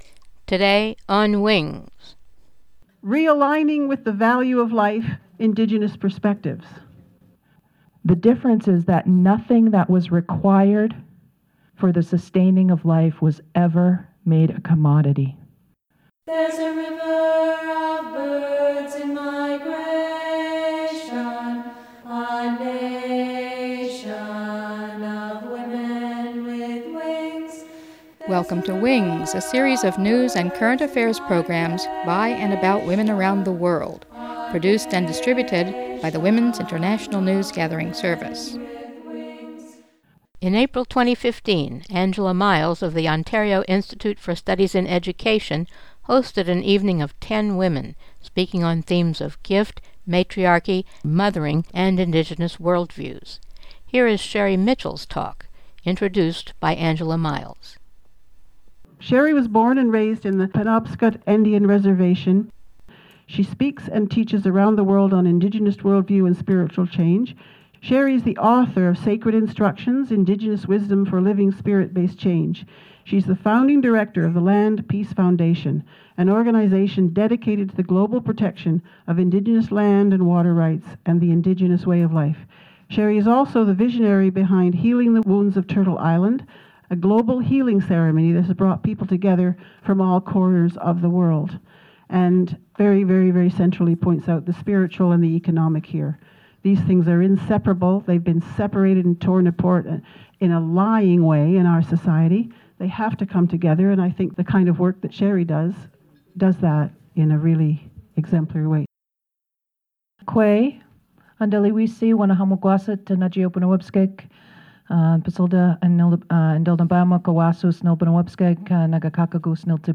This is the talk she gave at an event in Toronto, Ontario, Canada